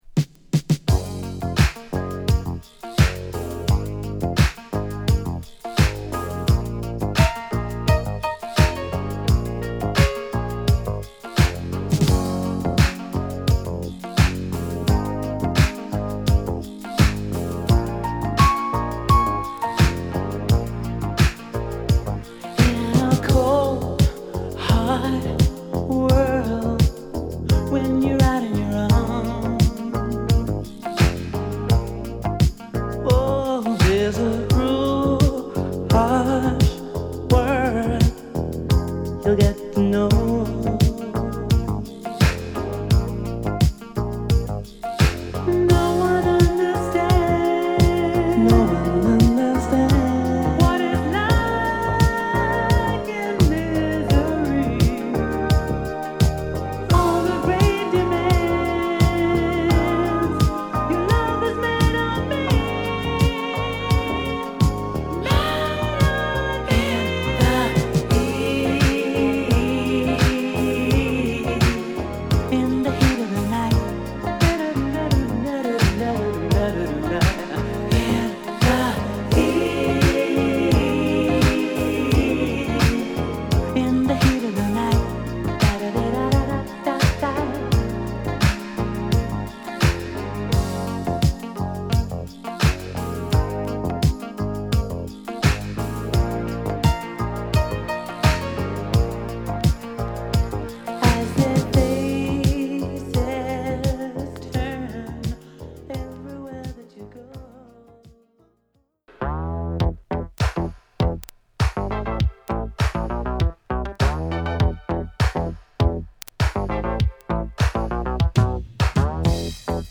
メロウ〜ブギーの好曲を満載！